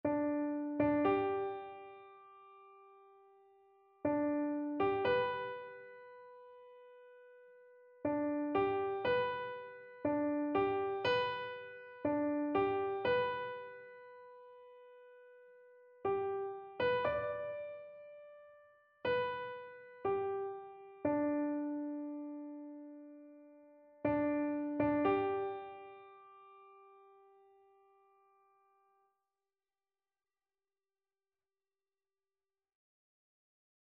No parts available for this pieces as it is for solo piano.
C major (Sounding Pitch) (View more C major Music for Piano )
4/4 (View more 4/4 Music)
Beginners Level: Recommended for Beginners
Piano  (View more Beginners Piano Music)
Classical (View more Classical Piano Music)
taps_PNO.mp3